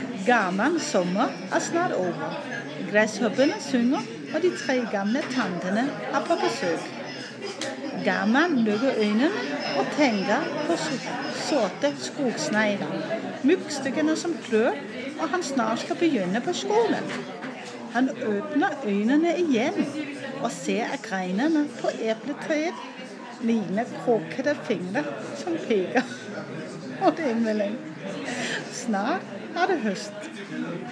Oplæsning af Garmann